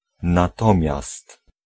Ääntäminen
IPA : /waɪl/